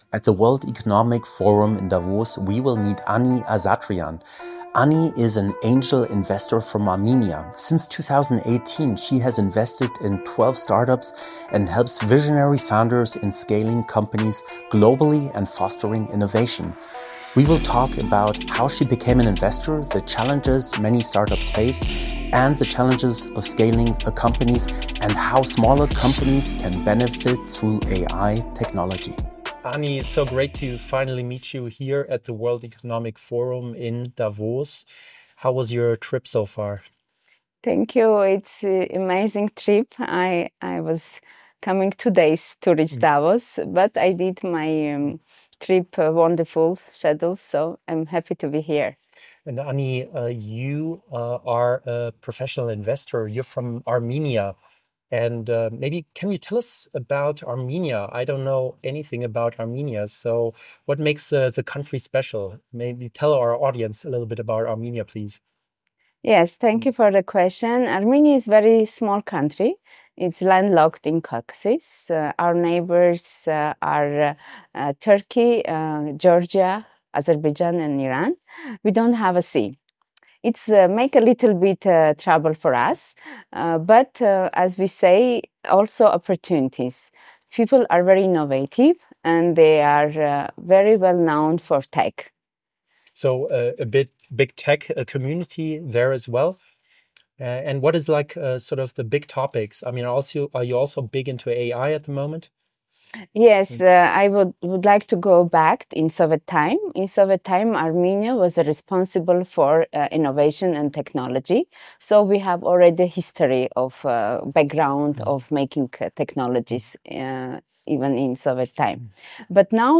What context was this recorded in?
recorded live at the World Economic Forum in Davos